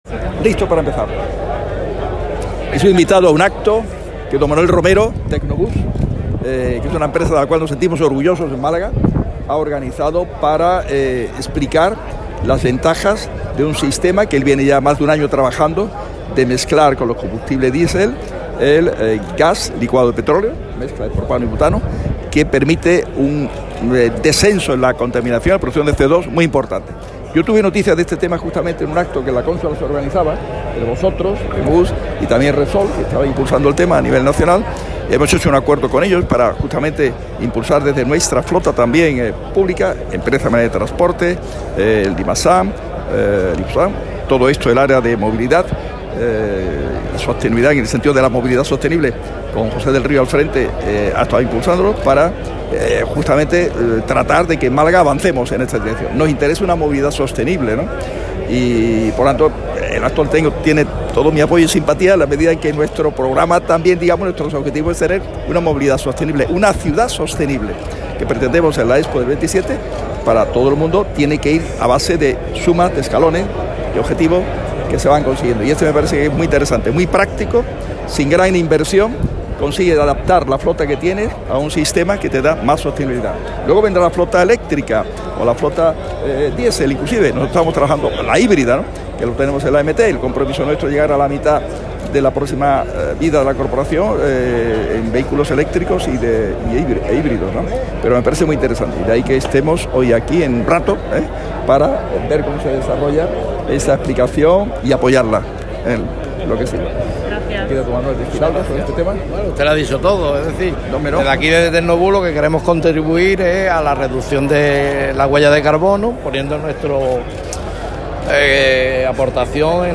Así lo ha expuesto durante un acto público en la barriada de El Pontil junto al alcalde y candidato a la reelección, Óscar Medina, marco en el que ha destacado que “estamos hablando de una cosa muy seria: ayudar a nuestros agricultores, a los subtropicales de la Axarquía”, afirmando que “en el PSOE son expertos en lanzar fuegos de artificio, en sacar conejos de la chistera y en presentarse como salvadores cuando es competencia del Gobierno hacer esa desaladora”.